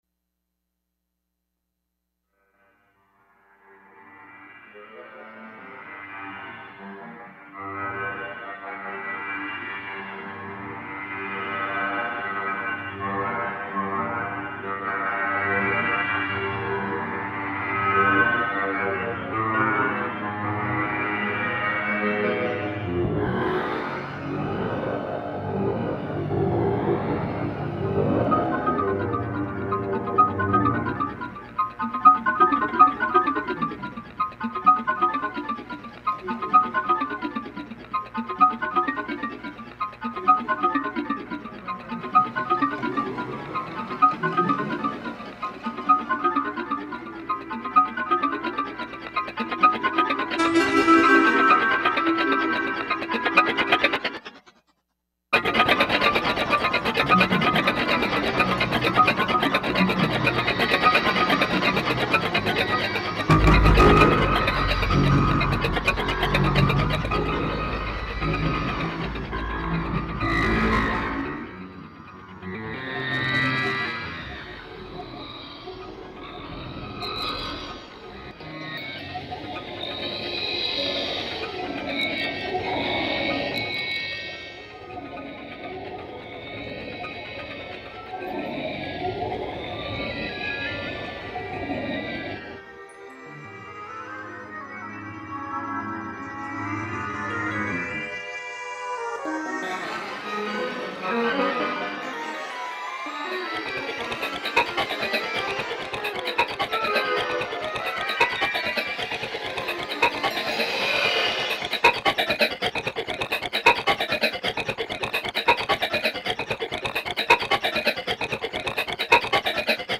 These were then scanned and the noises made by the scanner preserved as audio files, which the composer manipulated in the computer to build tracks.